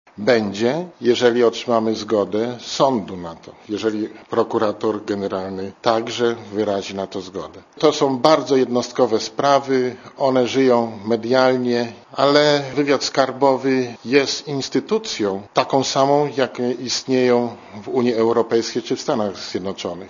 Dla Radia Zet mówi Ryszard Beldzikowski (78 KB)